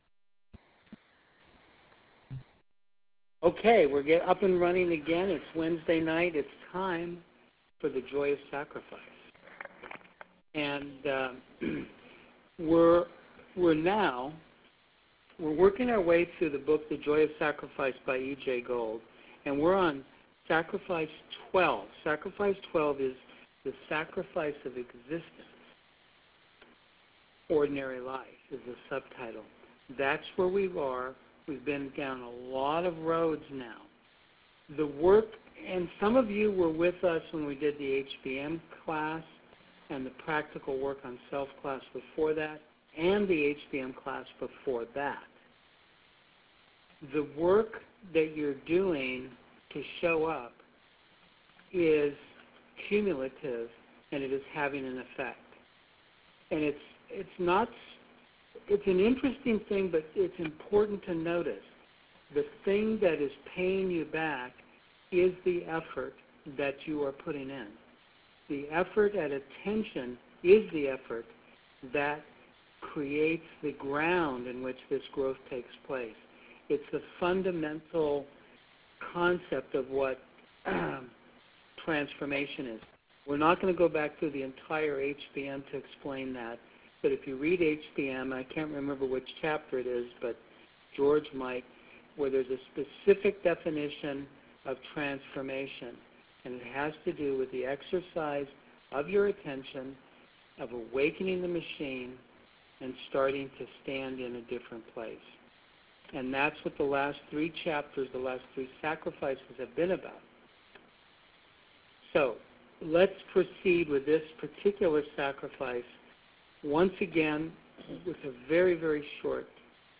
Here is a recording of the class held on this sacrifice: Audio Recording Class_15_Oct_12_2011 The log from the class can be viewed here .